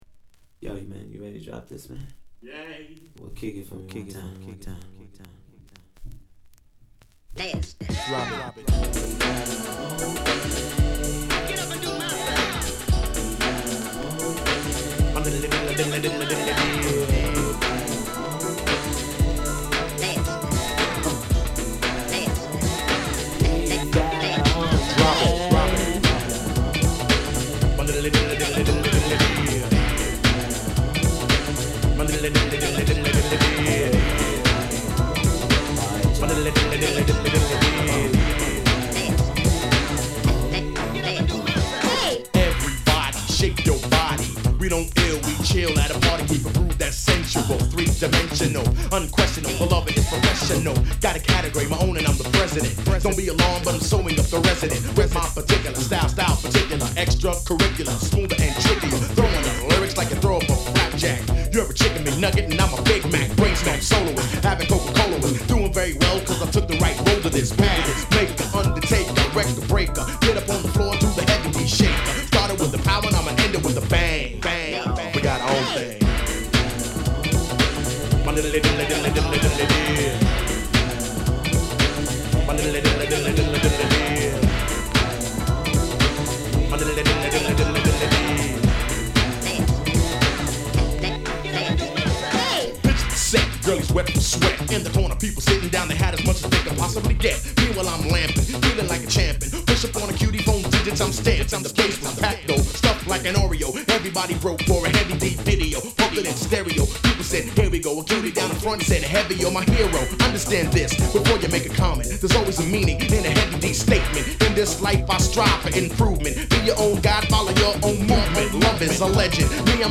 NJS以上Hip House手前の絶妙なトラックにラップを乗せた1枚！